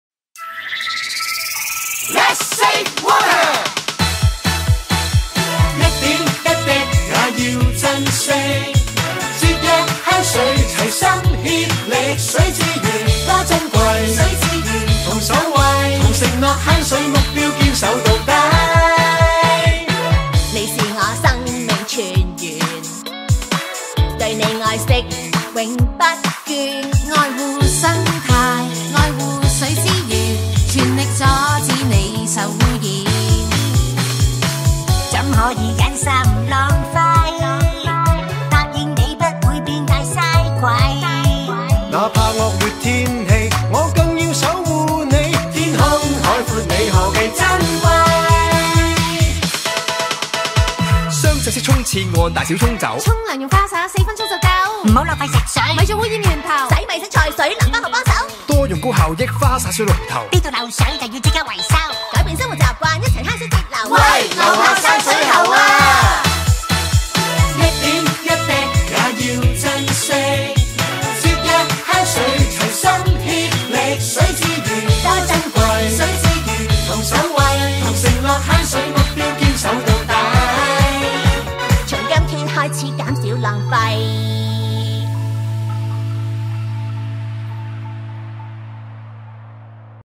由四位政府部門吉祥物組成男子組合